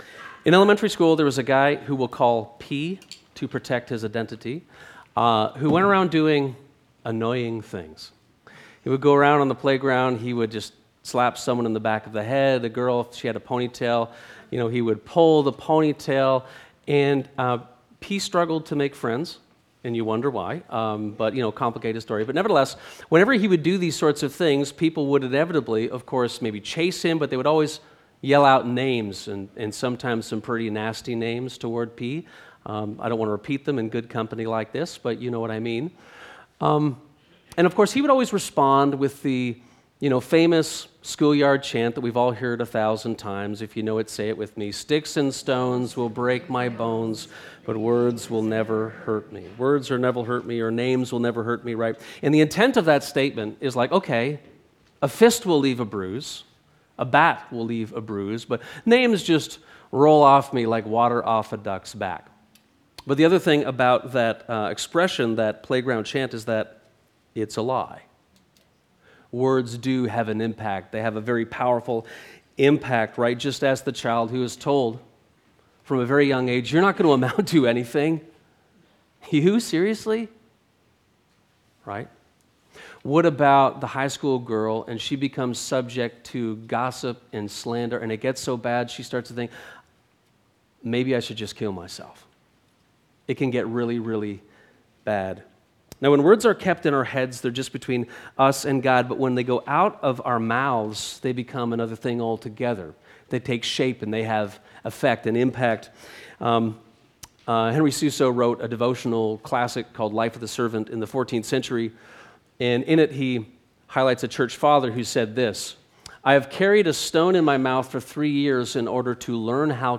This sermon on Mark 7:31-8:30 looks at four related sections of text which come to a head with Peter’s confession.